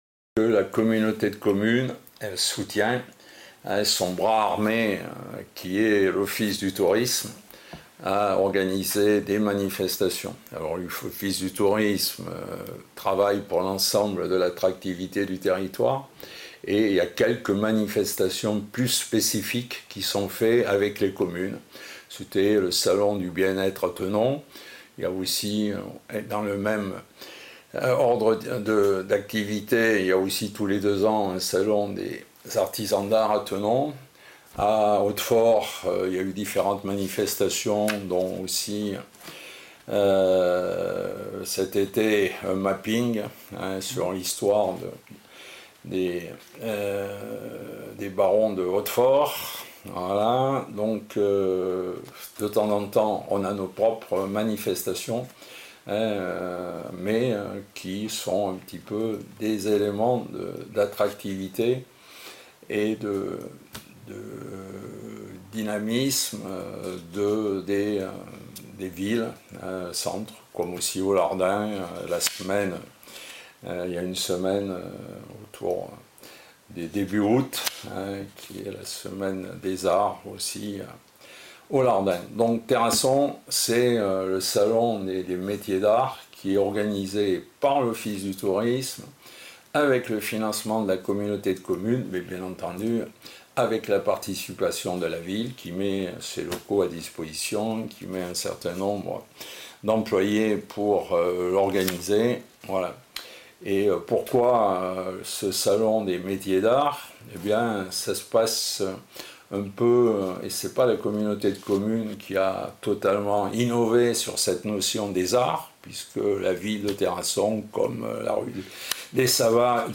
Ewanews était en direct du 4ème NAMMA, salon des métiers d’art à Terrasson-Lavilledieu du 18 octobre au 20 octobre 2024 à la salle des fêtes et la Vitrine du Périgord, avec près de 50 artisans d’art sélectionnés en Nouvelle Aquitaine et en France...